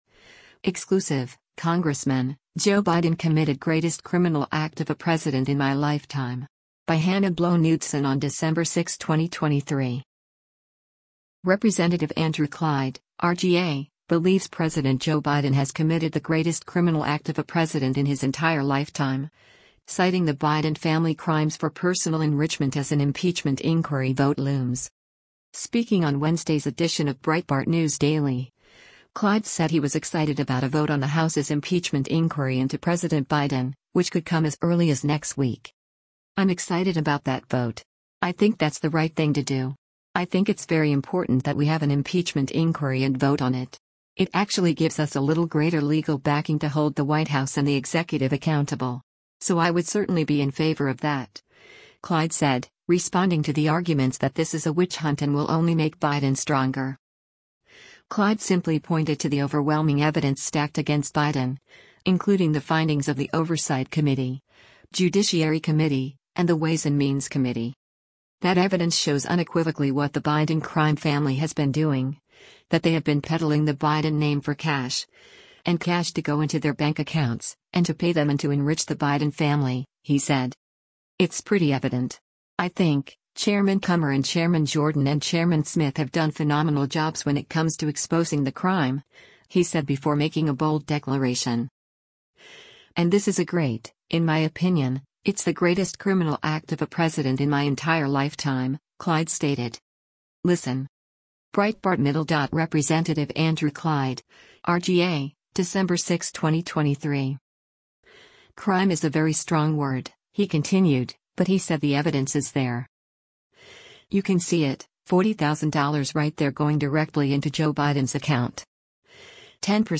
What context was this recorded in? Breitbart News Daily airs on SiriusXM Patriot 125 from 6:00 a.m. to 9:00 a.m. Eastern.